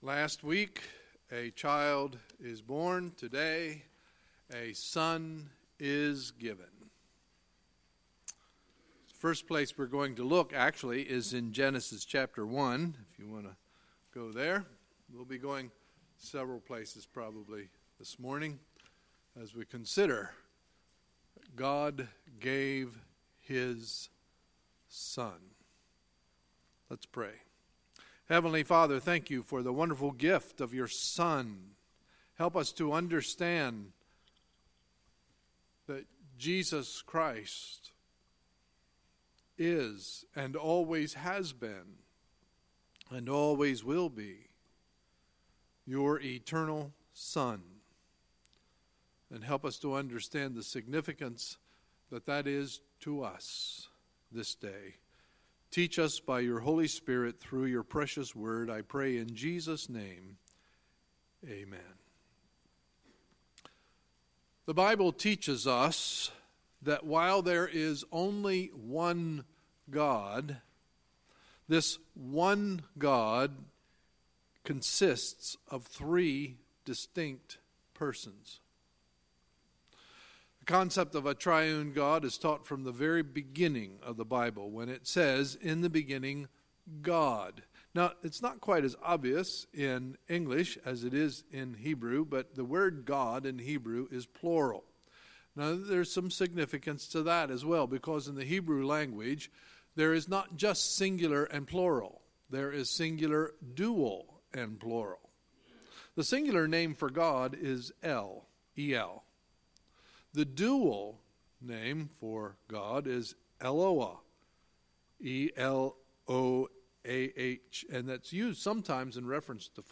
Sunday, December 10, 2017 – Sunday Morning Service